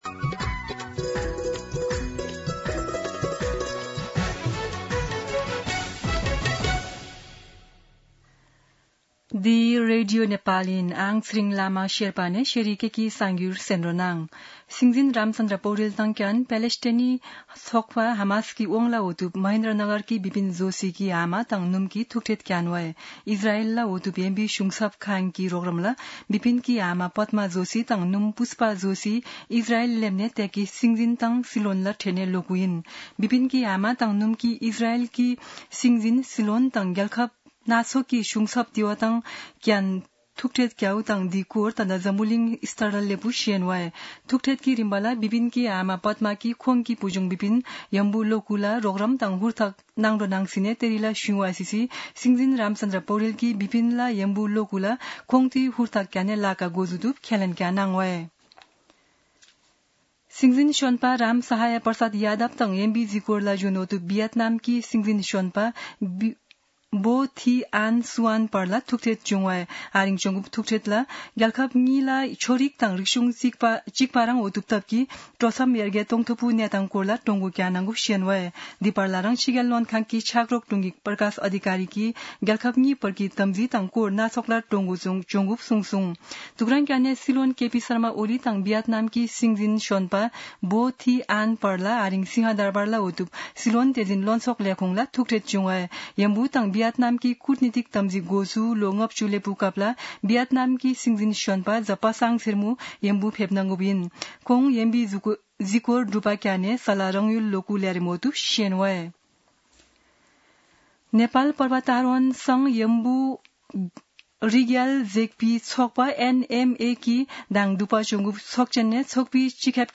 शेर्पा भाषाको समाचार : ८ भदौ , २०८२
Sherpa-News-08.mp3